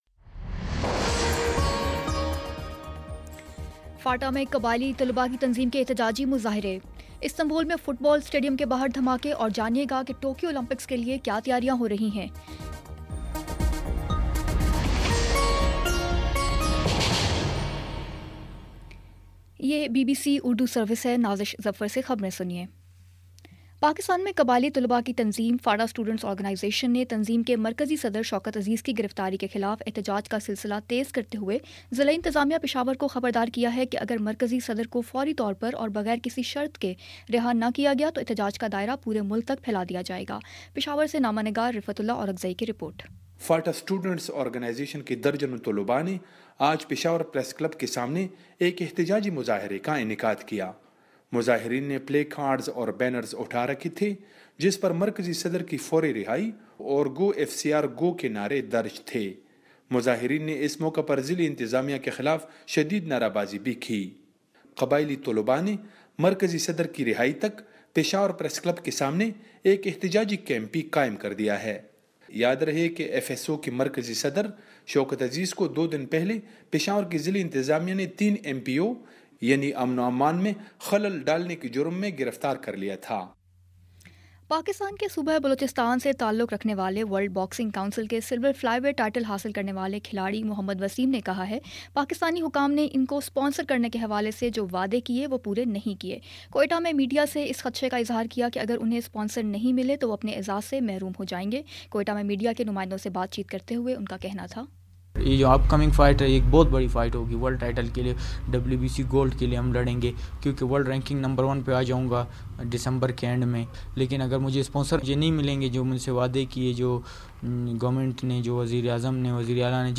دسمبر 11 : شام پانچ بجے کا نیوز بُلیٹن